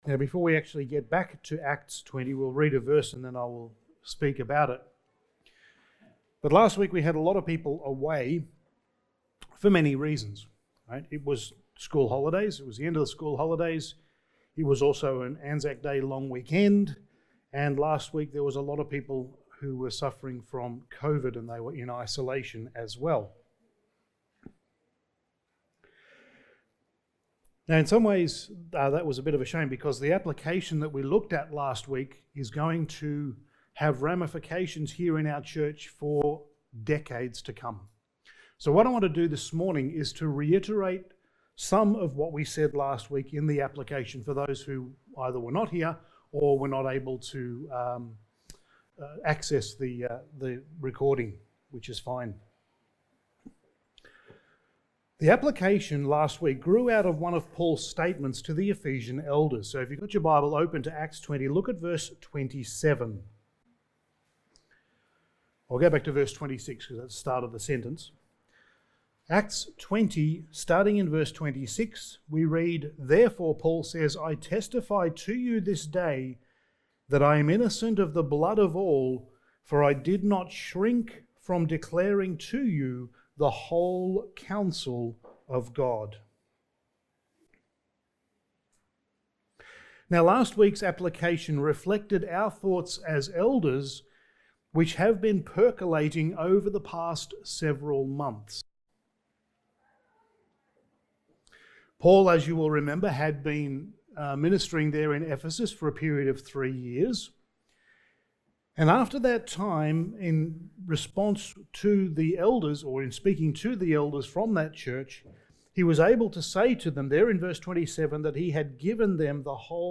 Passage: Acts 20:28-35 Service Type: Sunday Morning